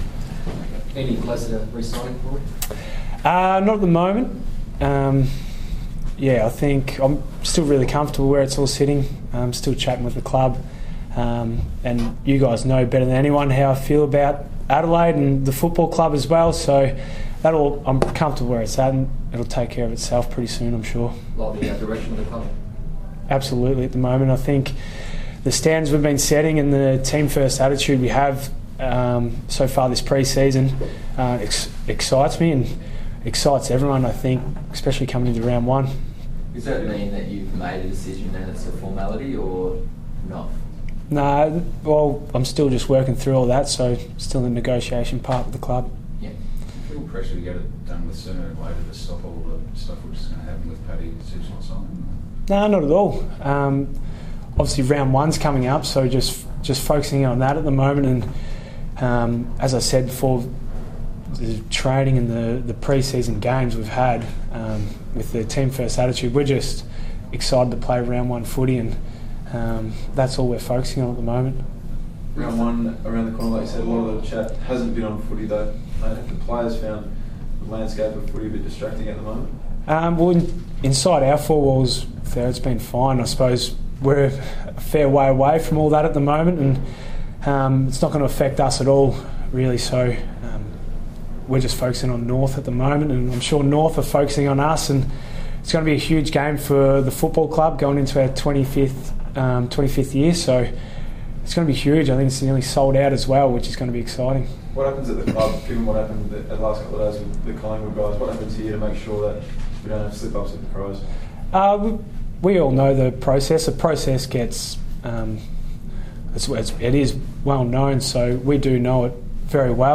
Rory Sloane Press Conference